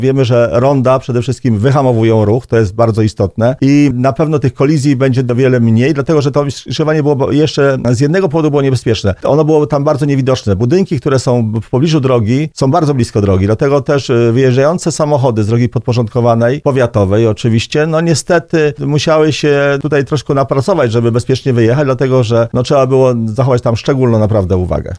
Jak mówił na naszej antenie starosta tarnowski Roman Łucarz, tego rodzaju zmiana pozwoliła w znacznej mierze rozładować ruch np. związany z licznymi pielgrzymkami do miejsca kultu bł. Karoliny Kózkówny.